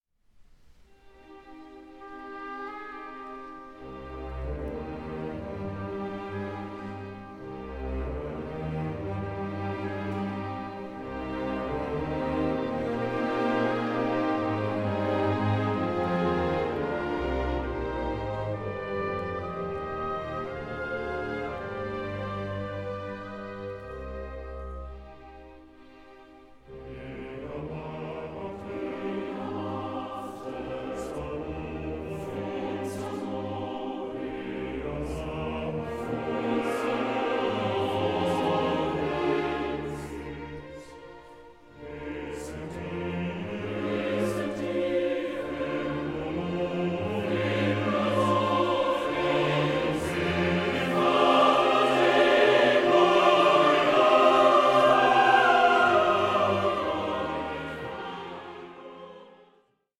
for soloists, choir and orchestra
Live recording